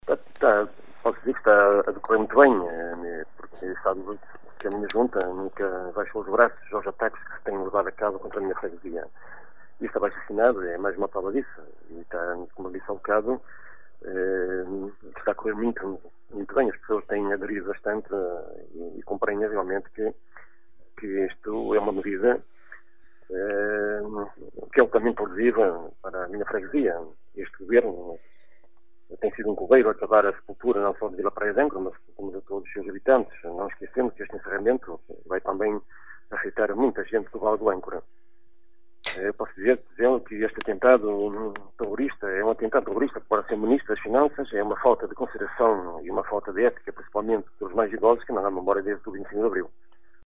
O autarca não poupa críticas ao actual Governo que, atira, tem “sido um coveiro” para Vila Praia de Âncora